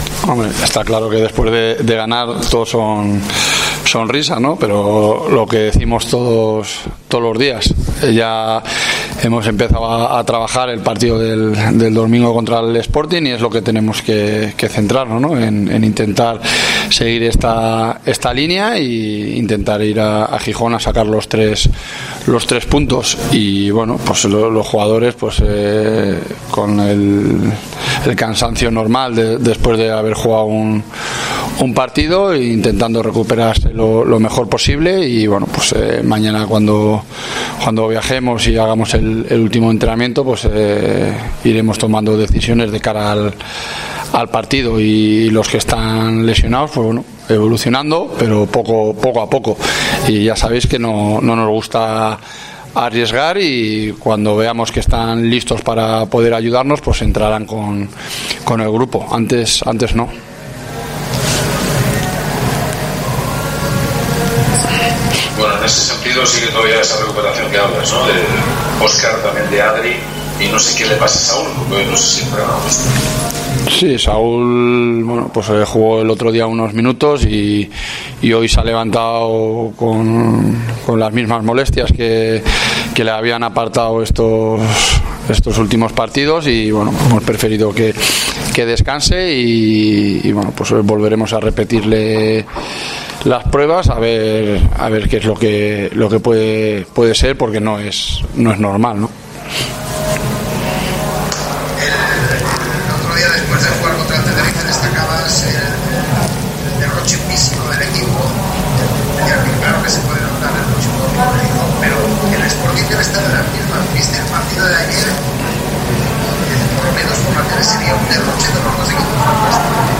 El entrenador de la Deportiva Ponferradina, Jon Pérez Bolo, ha analizado la actualidad del equipo berciano en la previa de la octava jornada de LaLaLiga SmartBank, donde los blanquiazules jugarán este domingo a las 16.00 horas, en tierras asturianas, en el estadio El Molinón, ante el Sporting de Gijón.